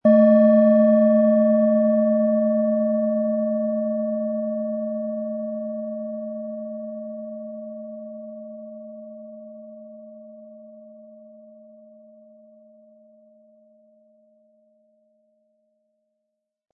Planetenton 1
Planetenschale® Spüre Deine weibliche Seite & Weibliche Kraft leben mit Venus, Ø 10,8 cm inkl. Klöppel
Im Sound-Player - Jetzt reinhören können Sie den Original-Ton genau dieser Schale anhören.
Harmonische Töne erhalten Sie, wenn Sie die Schale mit dem kostenfrei beigelegten Klöppel ganz sanft anspielen.